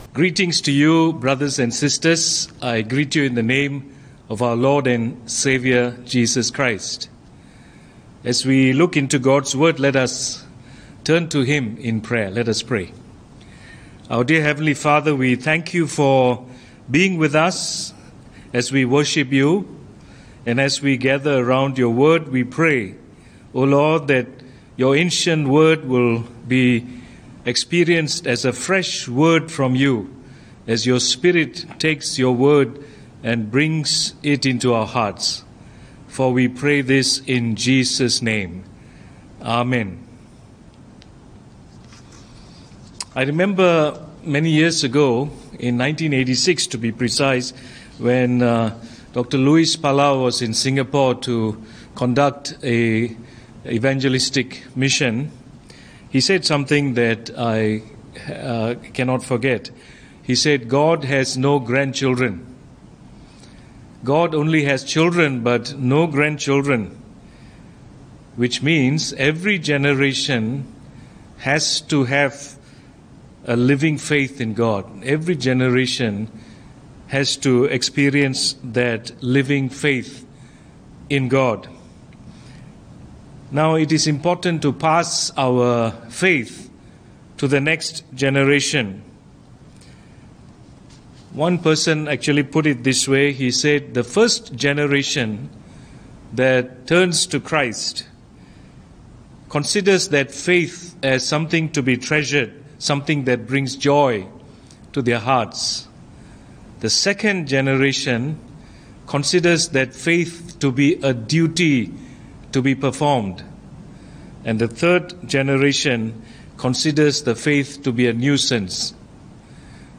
Bishop Emeritus Dr. Robert Solomon